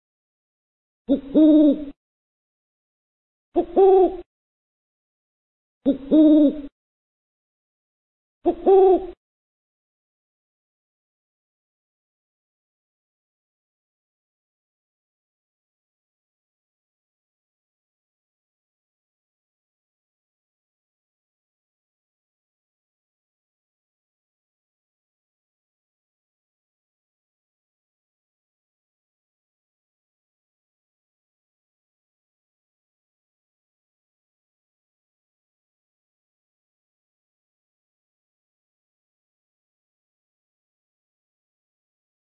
Owl
Owl.wav